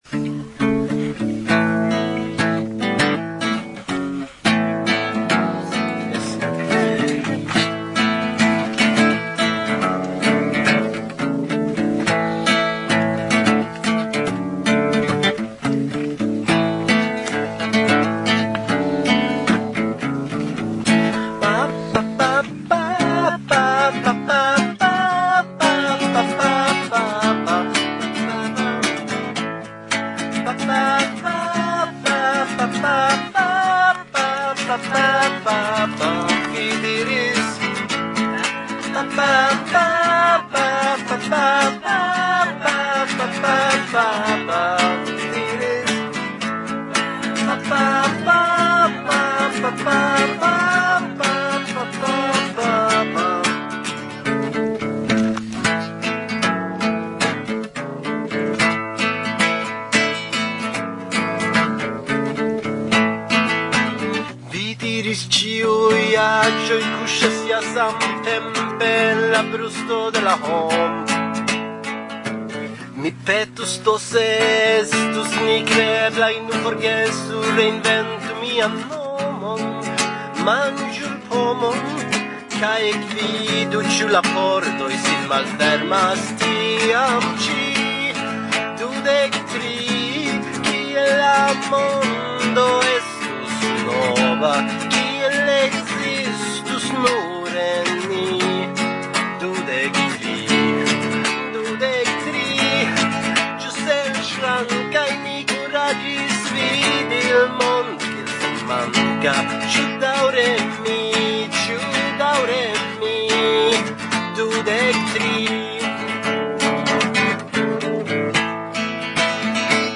• Fragmento de la koncerto dum MELA 2010
Tendaro en ĉirkaŭaĵo de Délegyháza / Hungario